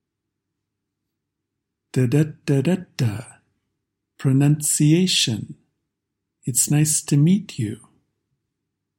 It’s the exact same stress pattern in all three examples.